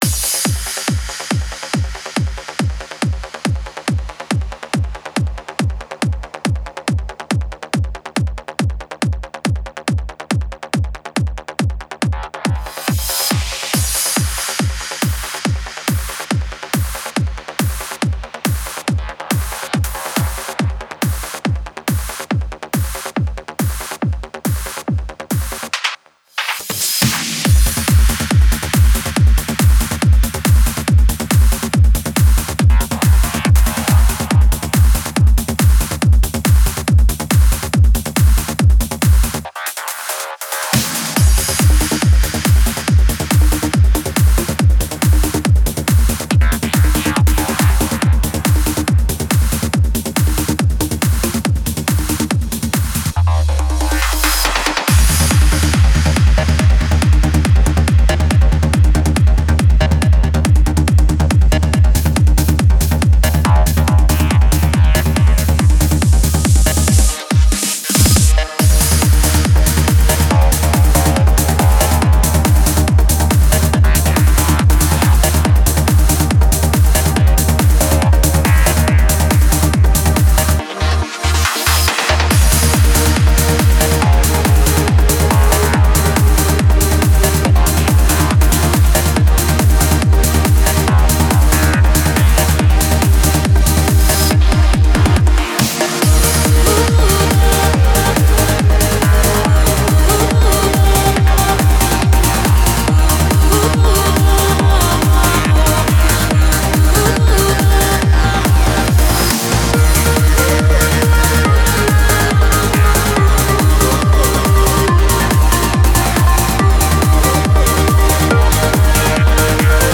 Стиль: Vocal Trance / Uplifting Trance